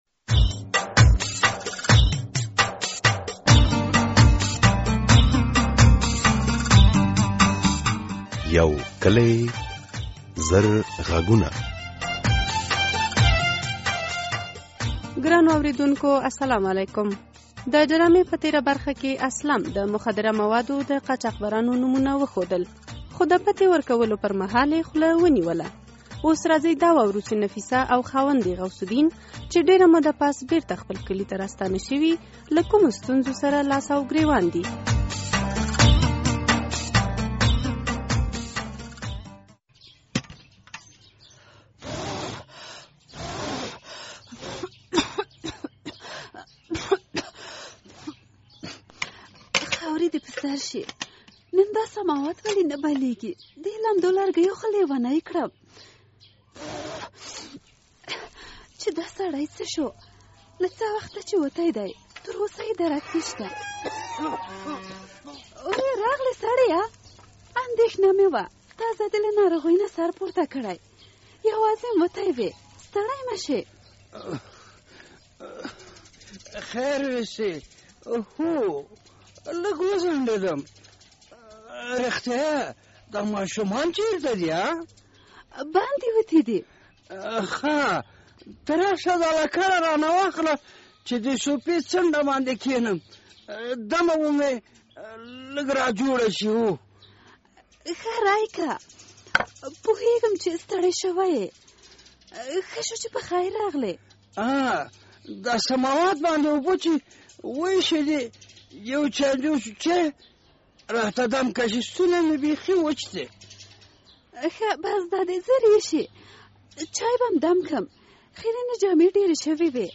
د یو کلي زر غږونو ډرامې ۱۴۱مه برخه